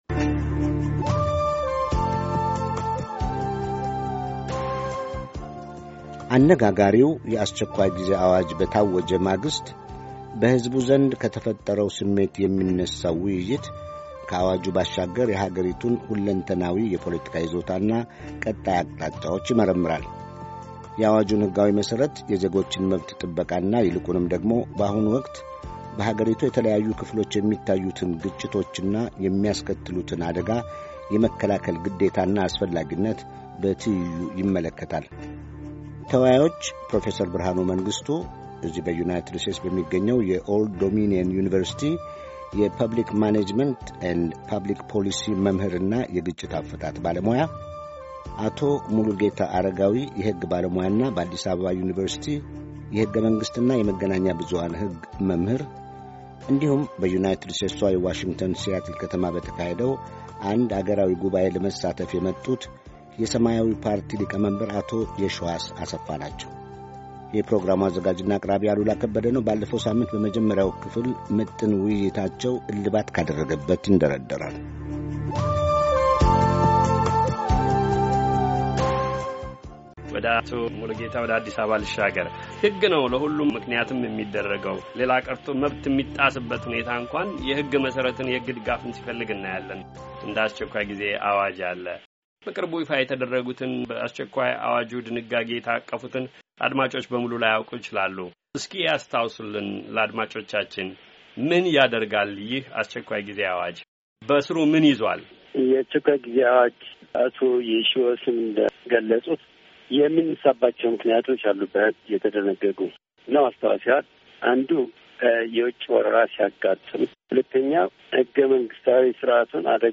ውይይት:- ኢትዮጵያ በአስቸኳይ ጊዜ አዋጁ ማግስት
የተከታታይ ውይይቱን የመጨረሻ ክፍል እነሆ